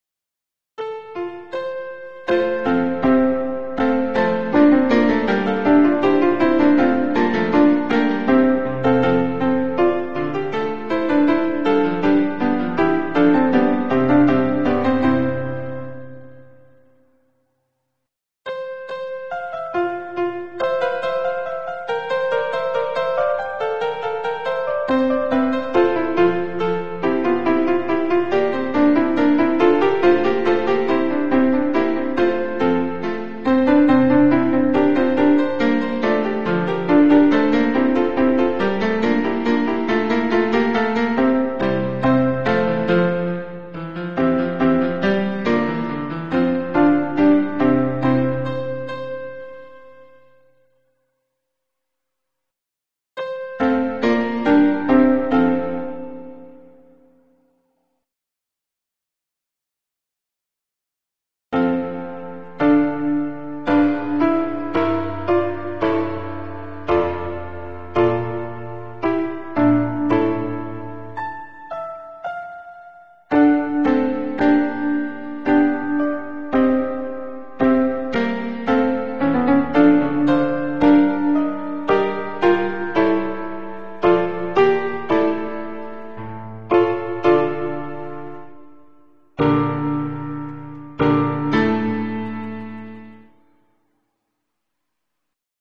MIDI
Sans paroles
Voix + chœur en sourdine